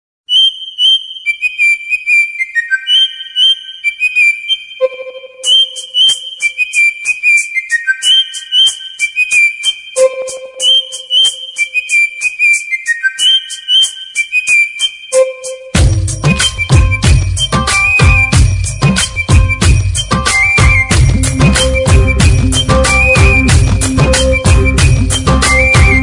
Flute_G_Five2.mp3